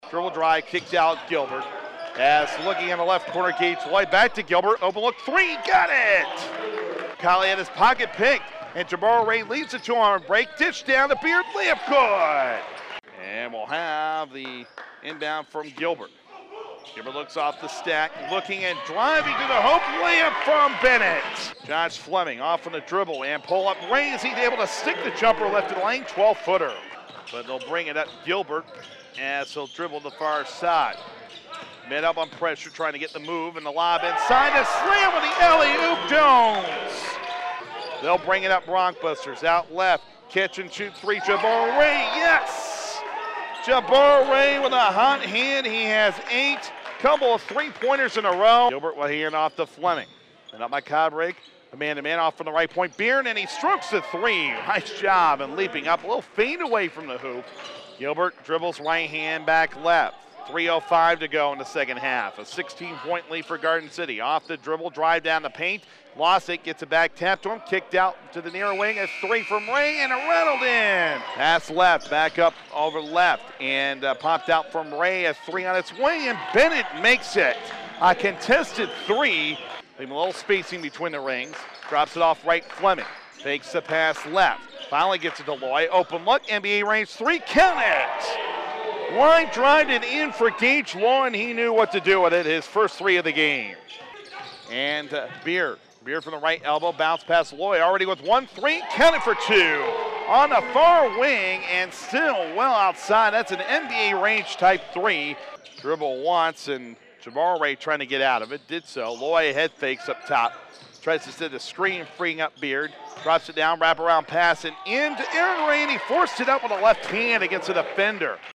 Game Highlights